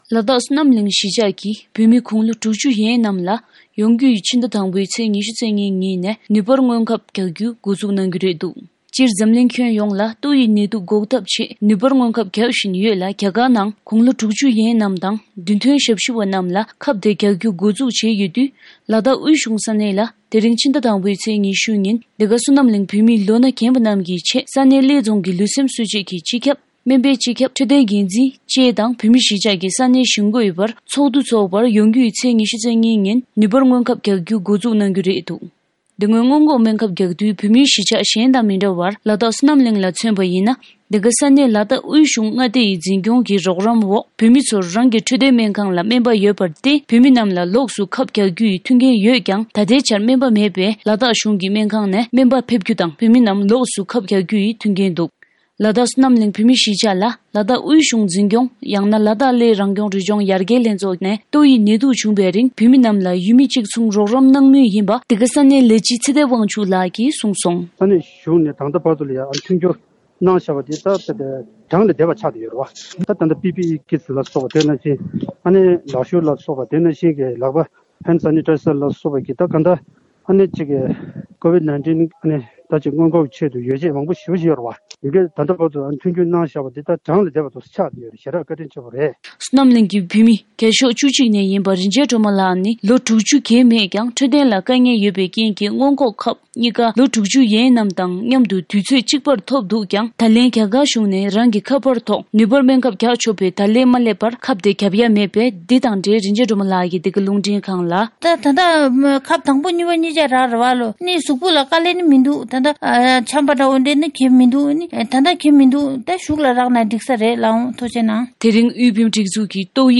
གསར་འགོད་པ།